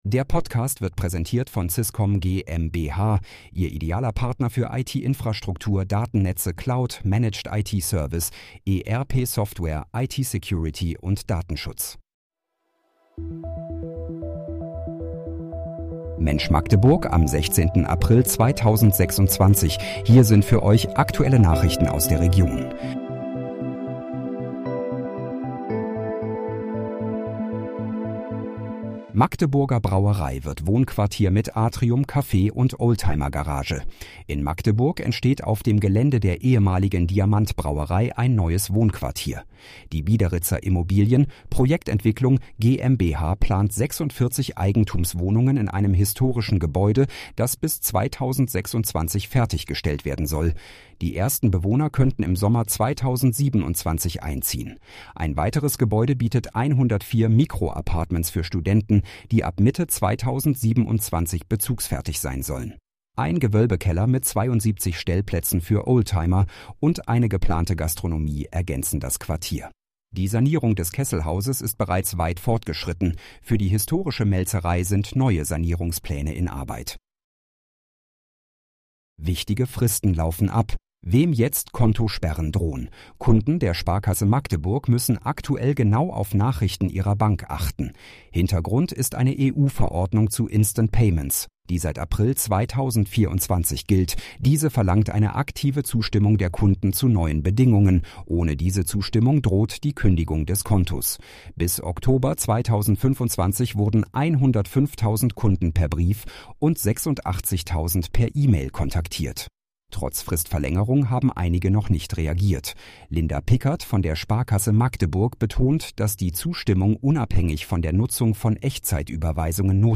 Mensch, Magdeburg: Aktuelle Nachrichten vom 16.04.2026, erstellt mit KI-Unterstützung